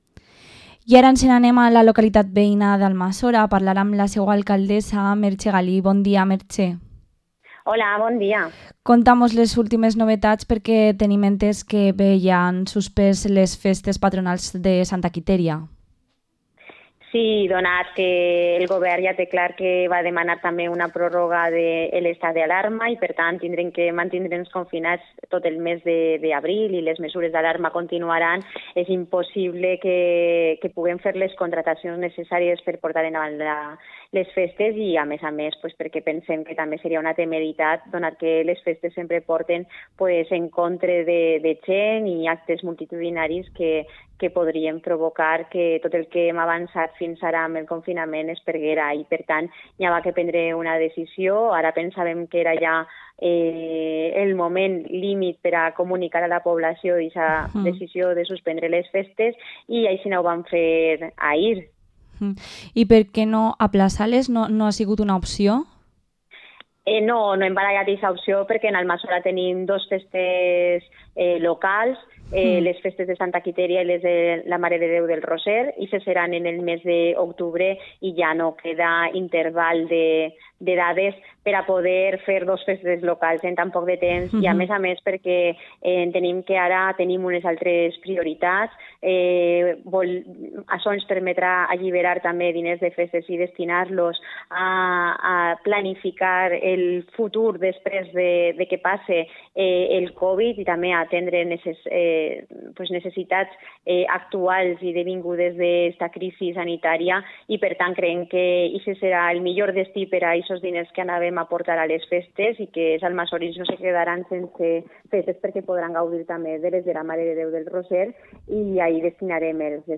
Entrevista a la alcaldesa de Almazora, Merche Galí